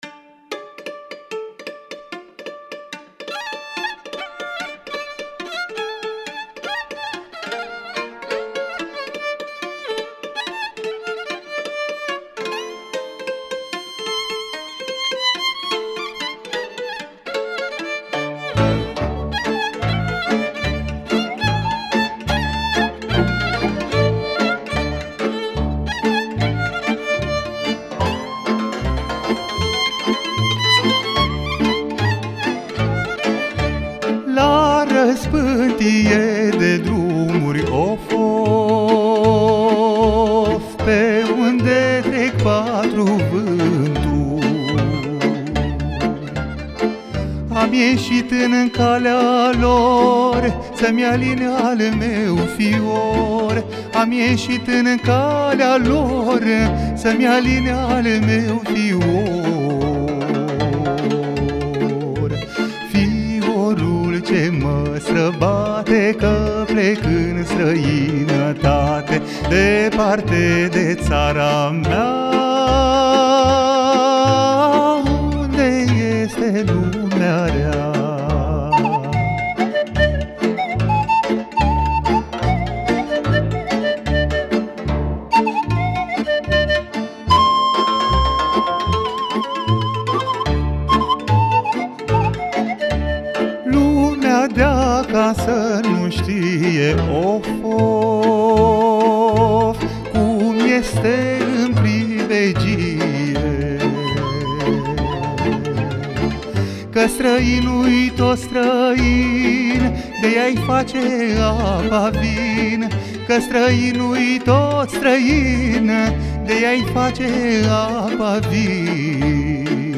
(AUDIO) Regal folcloric pe 909 KHz - Știri Constanța - Radio Constanța - Știri Tulcea
în direct
acordeon
vioară
cântecul tradiţional